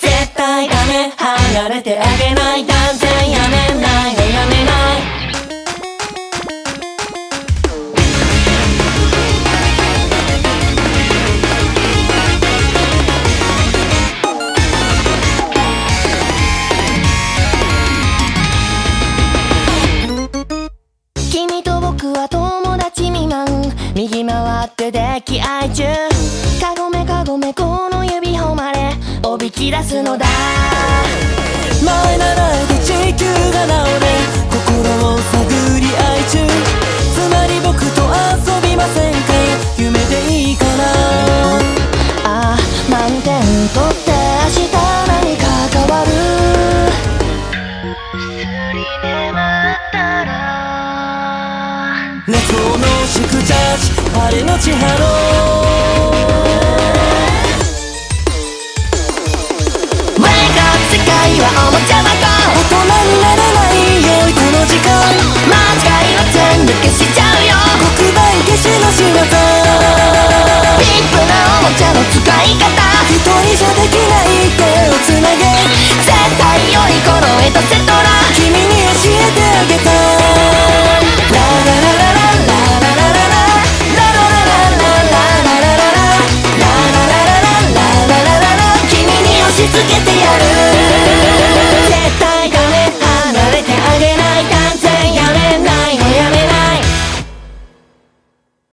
BPM91-364
MP3 QualityMusic Cut